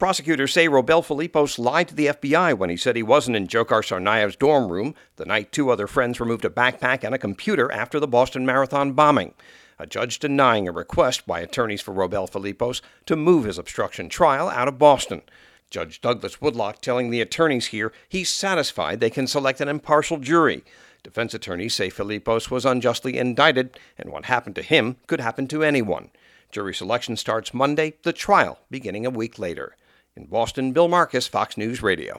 REPORTS FROM BOSTON.